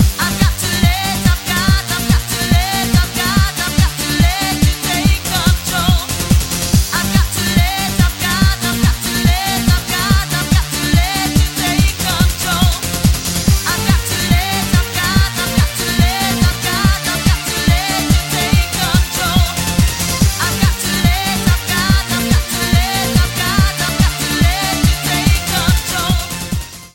евродэнс